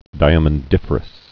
(dīə-mən-dĭfər-əs, dīmən-)